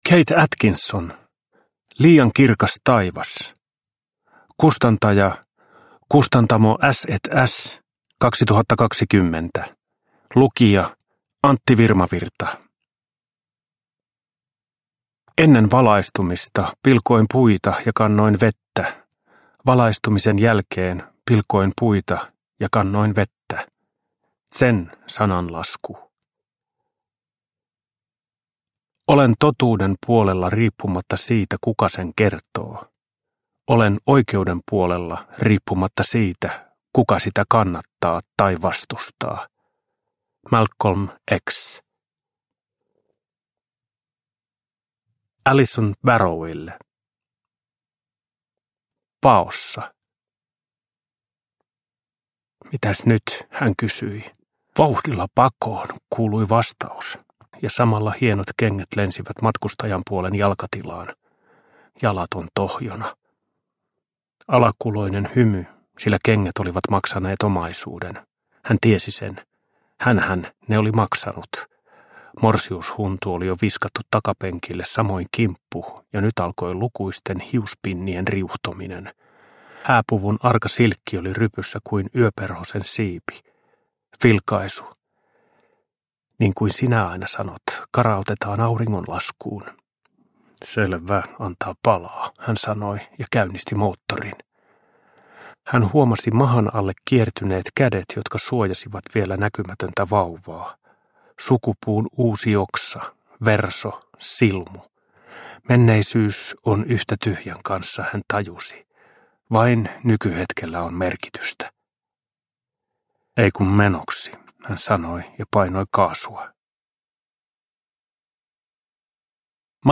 Liian kirkas taivas – Ljudbok – Laddas ner